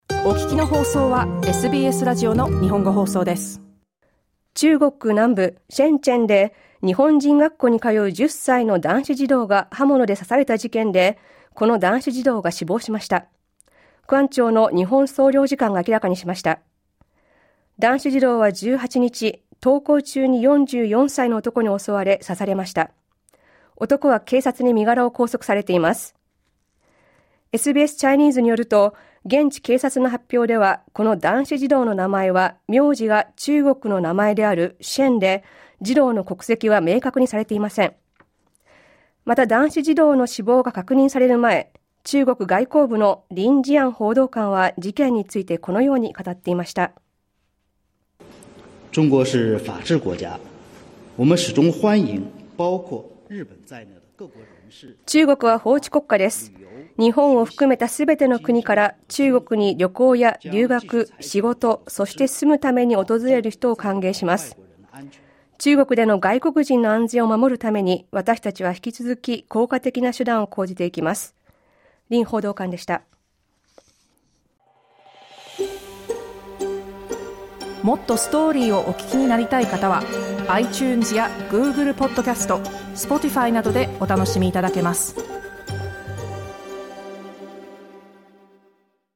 ＊音声は、９月19日のラジオ番組で放送されたニュースからの抜粋です。